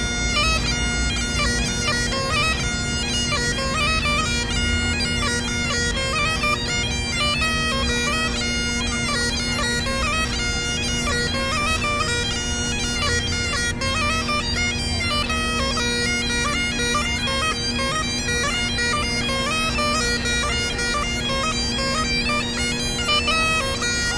Cornemuse (écossaise)
Famille : vent/bois
Cet air va dans les tuyaux, qui jouent le ou les bourdons, et dans le hautbois (muni de trous), qui permet de jouer les mélodies.
Le petit plus : la particularité de la cornemuse est d’avoir une réserve d’air dans une poche qui s’effectue à l’aide d’un tuyau et qui permet de jouer un son en continu (les autres instrumentistes à vent doivent reprendre leur souffle pour continuer de jouer).